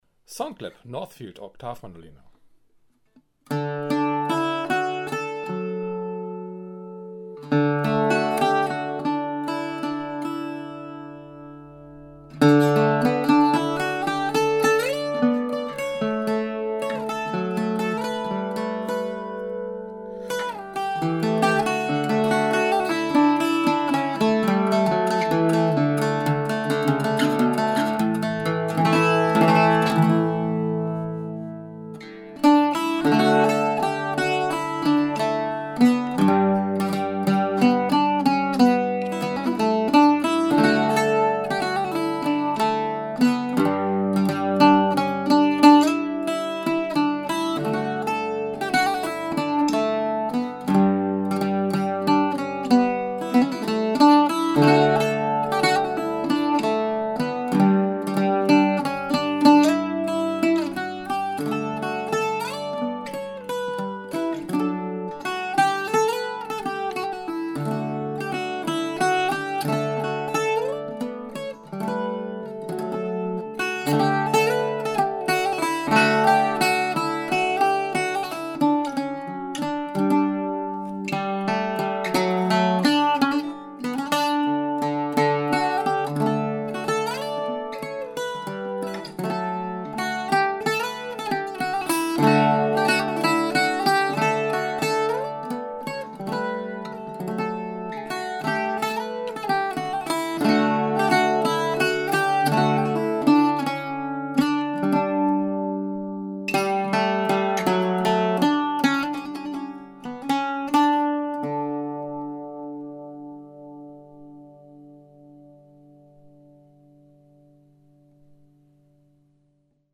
Northfield Archtop Octave Mandolin
Northfield Oktav Mandoline_3.mp3